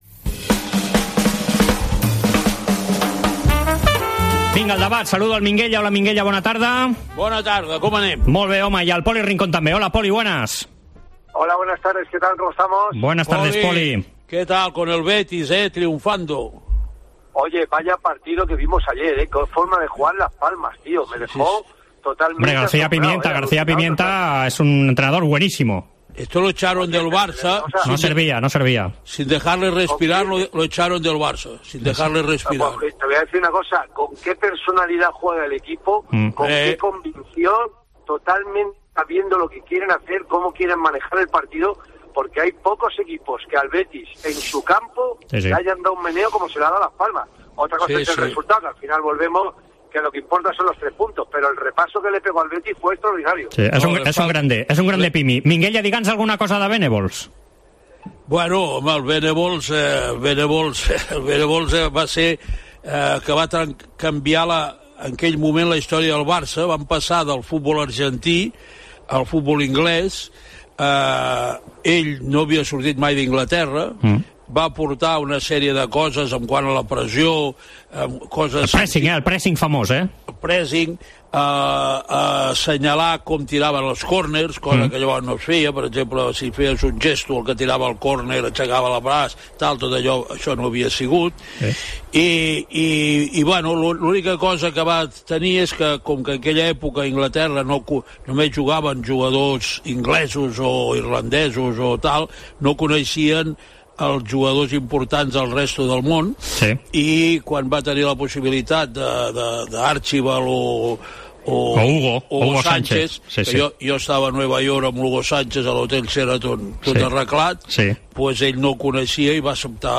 El debat Esports COPE, amb Poli Rincón i Minguella
AUDIO: Els dos col·laboradors de la Cadena COPE repassen l'actualitat esportiva de la setmana.